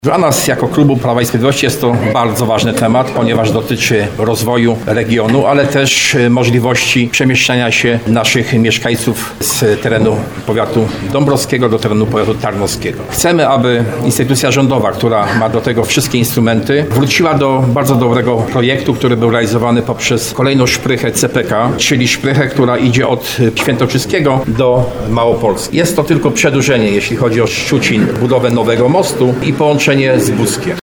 Starosta Jacek Hudyma na briefingu poprzedzającym sesję, zwrócił uwagę, że taka inwestycja miała być częścią projektu tzw. Siódmej Szprychy, czyli programu kolejowego Centralnego Portu Komunikacyjnego.